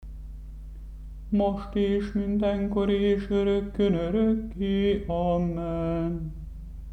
7. Most és... konták, 4. hang.mp3